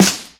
Closed Hats
pshh_snr.wav